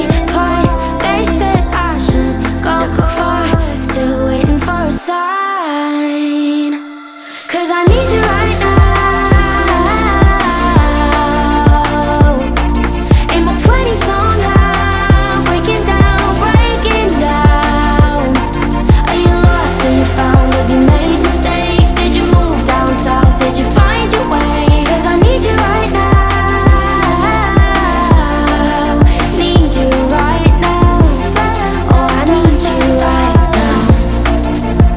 Pop Electronic
Жанр: Поп музыка / Электроника